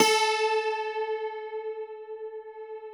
53q-pno13-A2.aif